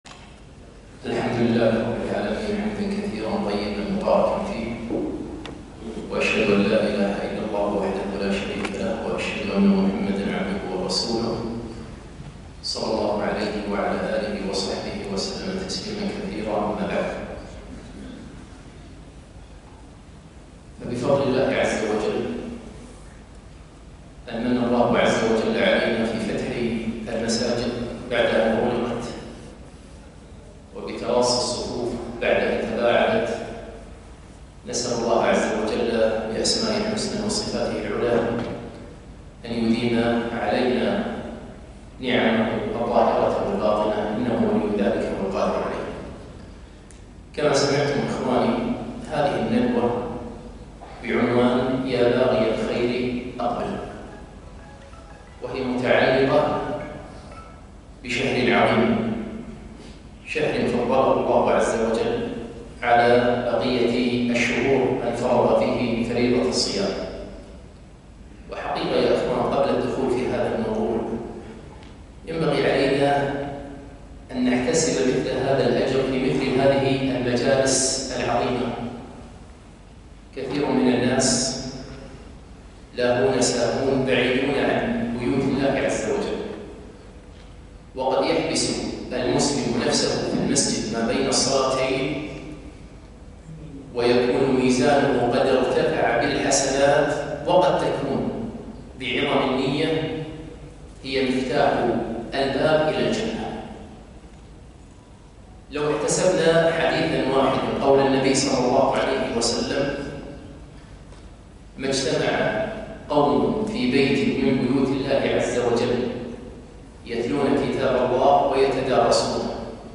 ندوة علمية - (يا باغي الخير أقبل)